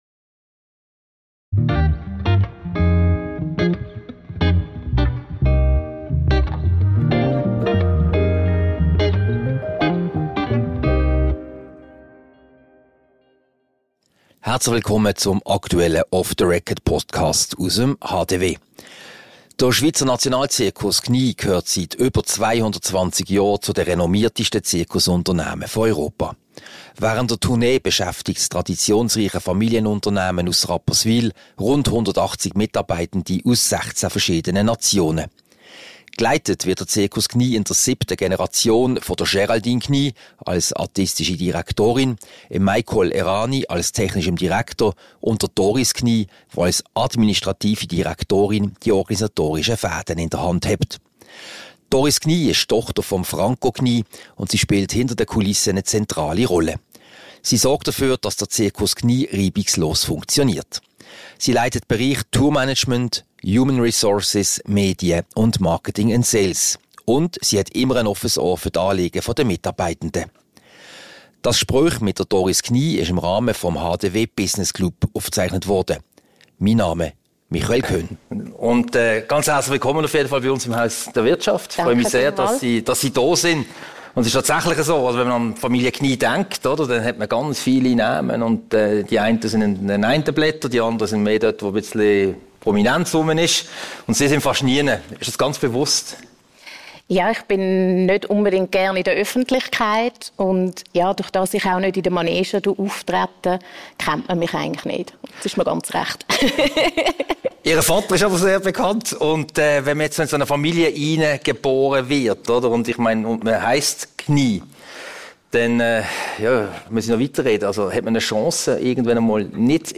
Diese Podcast-Ausgabe wurde am HDW Business Club Lunch vom 5. Dezember 2024 im Haus der Wirtschaft HDW aufgezeichnet.